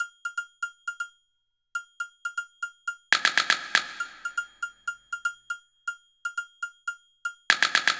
Bp Perc Loop.wav